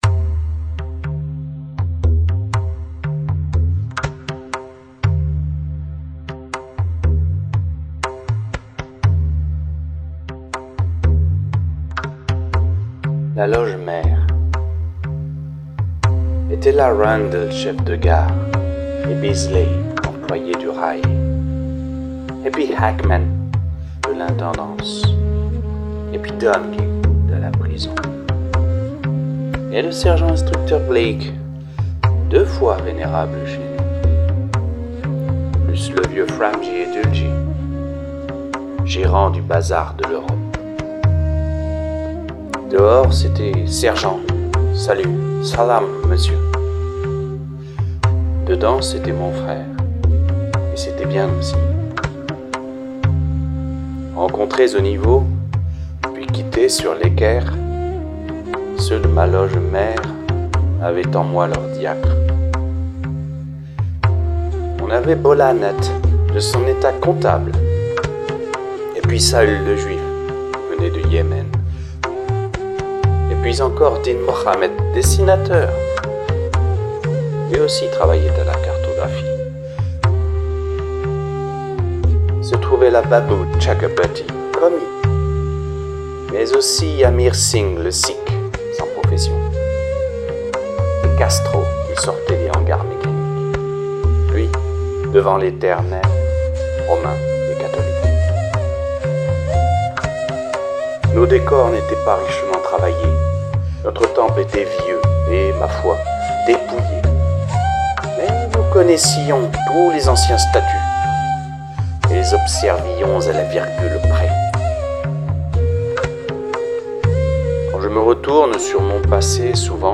Lecture en mp3 (2023)
KIPLING-EN-MUSIQUE.mp3